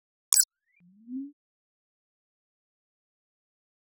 Futurisitc UI Sound 16.wav